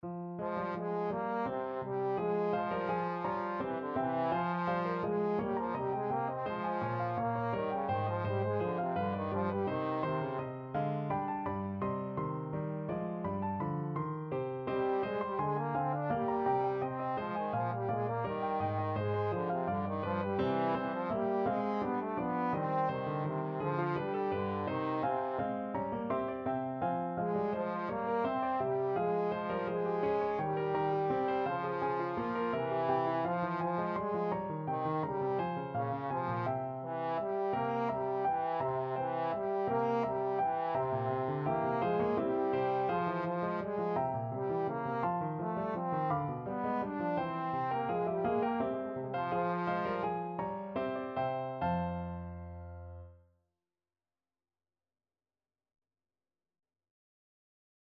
Trombone
3/8 (View more 3/8 Music)
F minor (Sounding Pitch) (View more F minor Music for Trombone )
Classical (View more Classical Trombone Music)